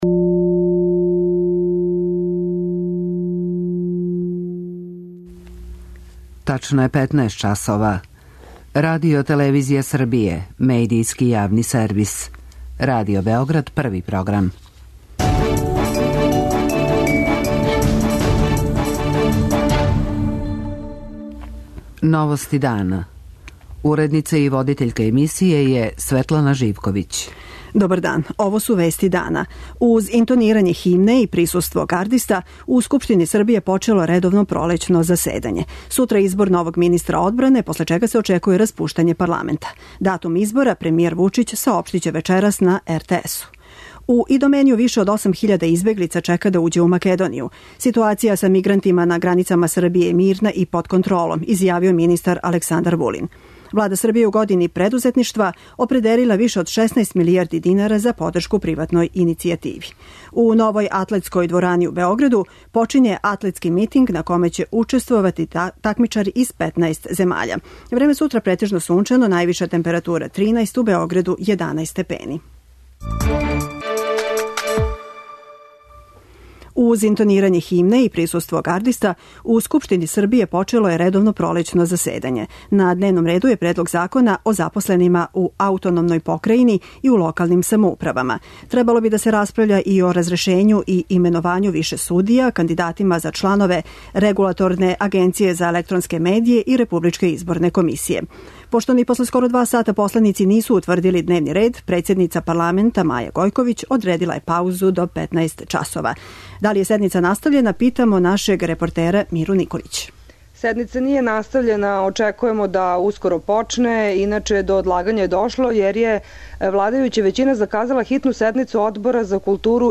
Уз интонирање химне и присуство гардиста, у Скупштини Србије је почело редовно пролећно заседање.